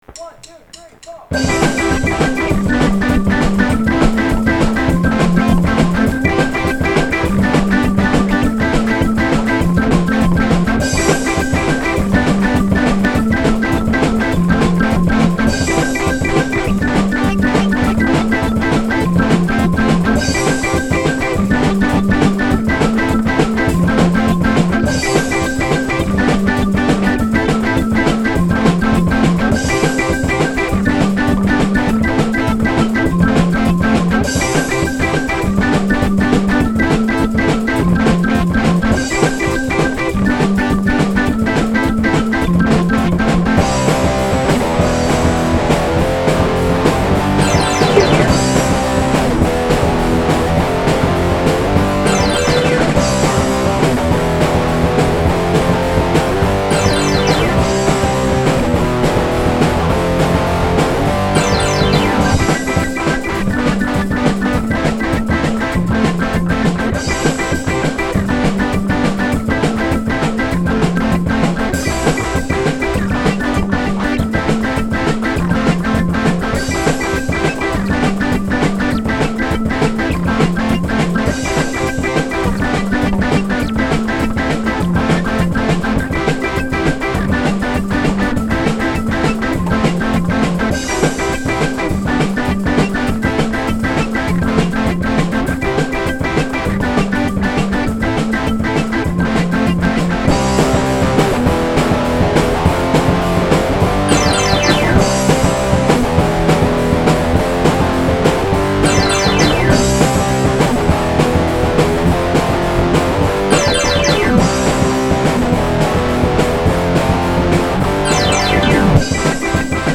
Ska Song
Instrumental completed; no lyrics/vocals